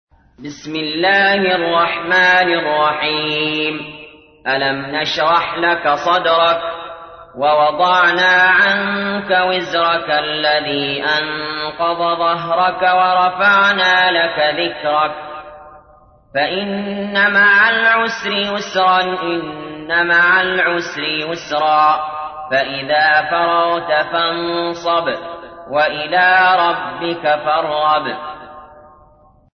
تحميل : 94. سورة الشرح / القارئ علي جابر / القرآن الكريم / موقع يا حسين